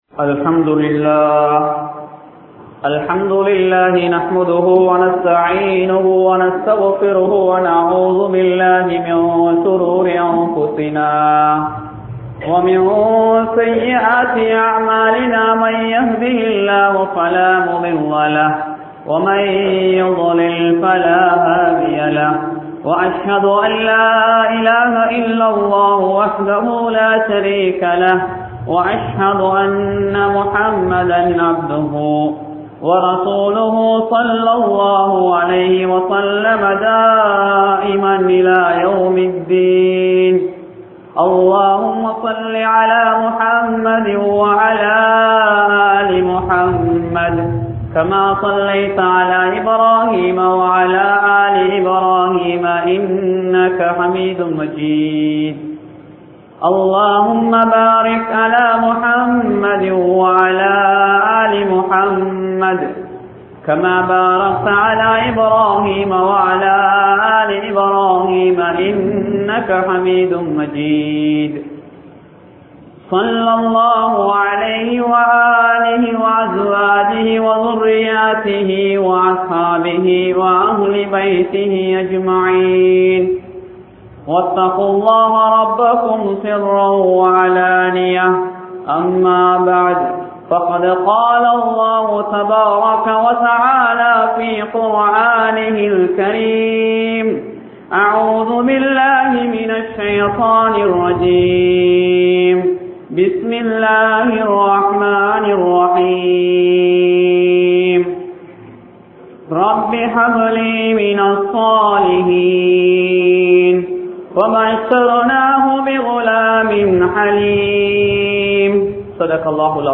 Siyambalagaskottuwa Jumua Masjidh